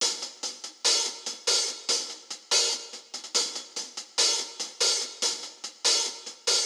I Dont Care Hi Hat Loop.wav